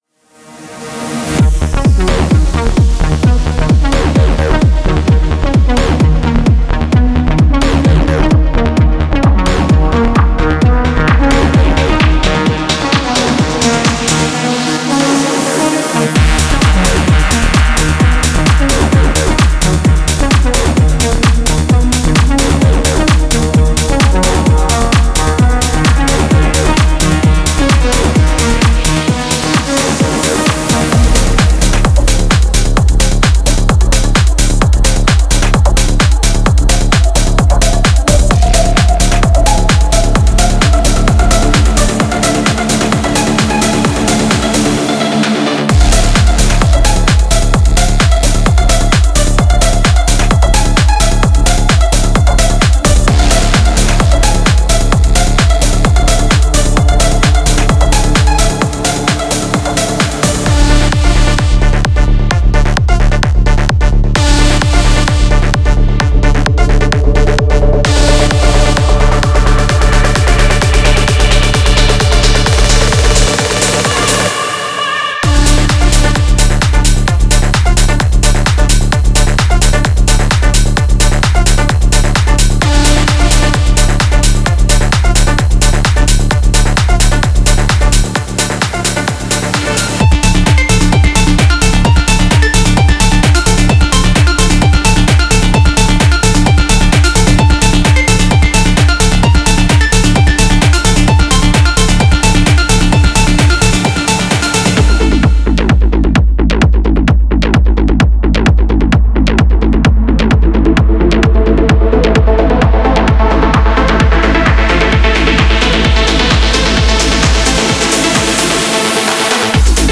Tech House Techno